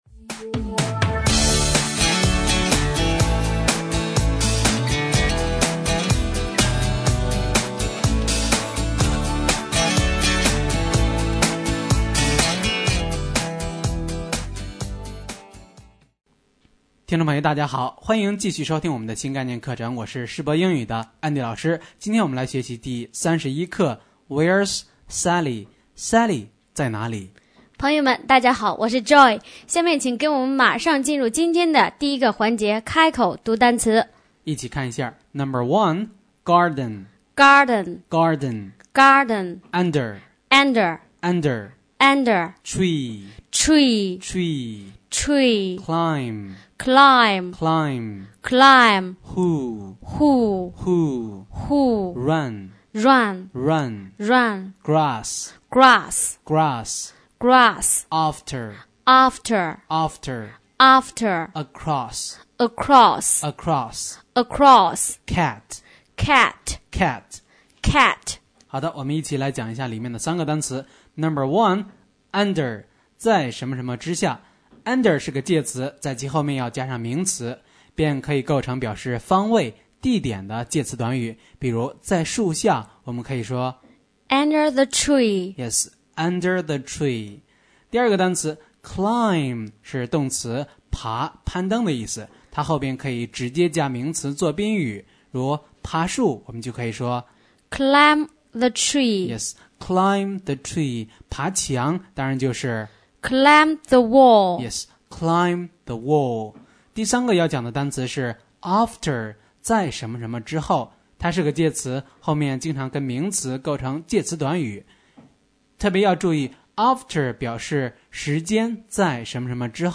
新概念英语第一册第31课【开口读单词】